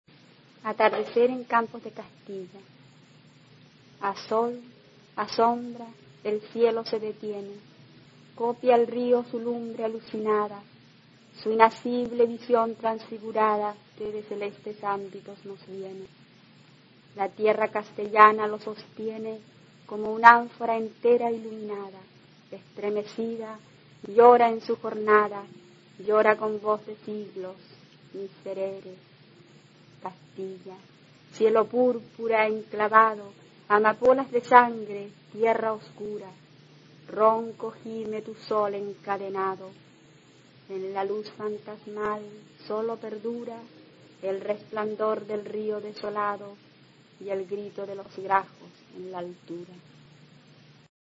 recitando el soneto Atardecer en campos de Castilla